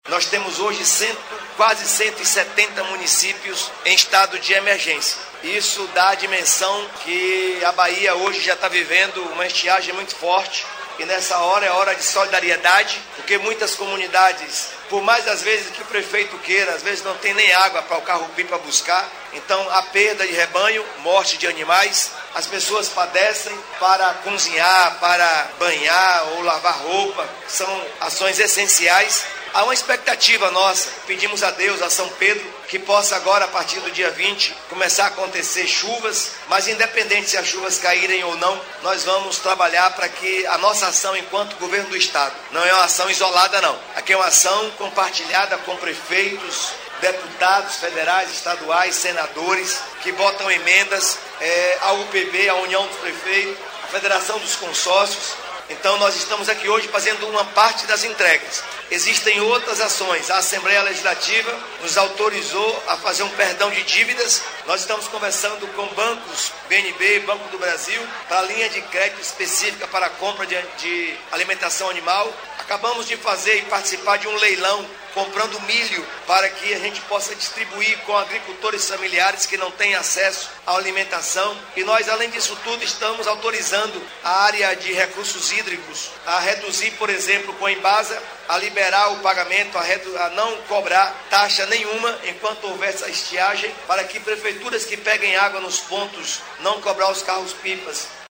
🎙 Jerônimo Rodrigues – Governador da Bahia